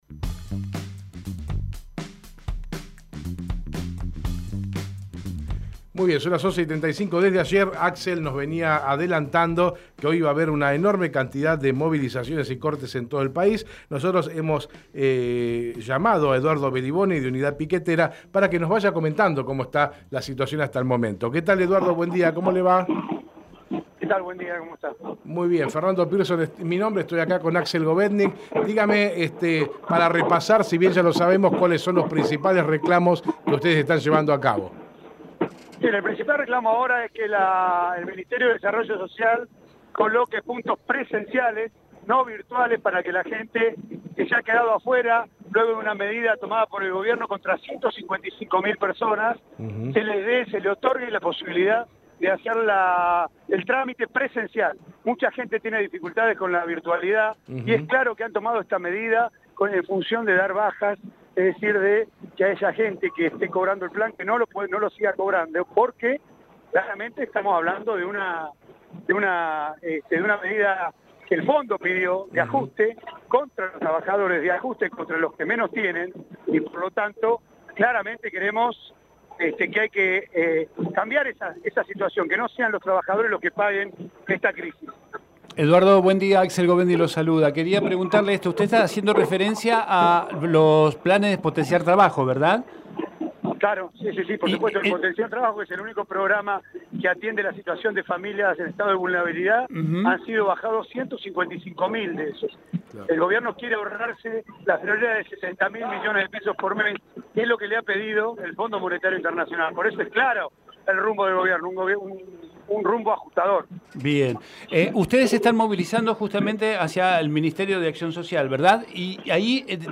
Eduardo Belliboni en Hacemos PyE Texto de la nota: Compartimos la entrevista realizada en Hacemos PyE a Eduardo Belliboni, dirigente de Unidad Piquetera . Conversamos sobre las movilizaciones y los reclamos que están llevando adelante les trabajadores.